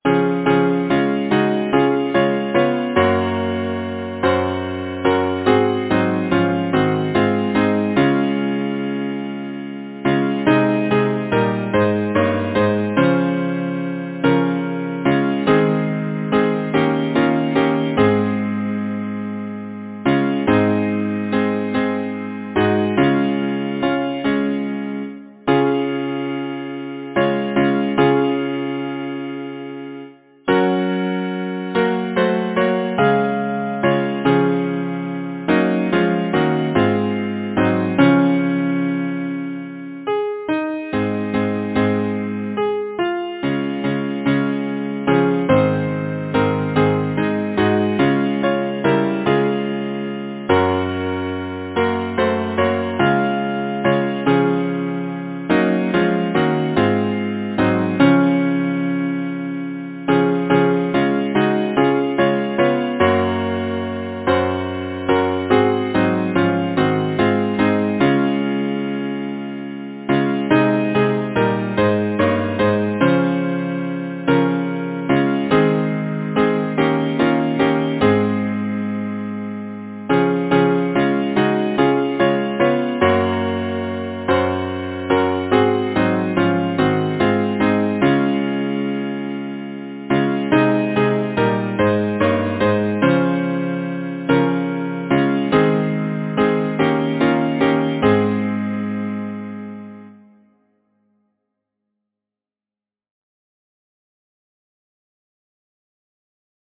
Title: The sweet voice Composer: Hubert Platt Main Lyricist: Fanny Crosby Number of voices: 4vv Voicing: SATB Genre: Secular, Partsong
Language: English Instruments: A cappella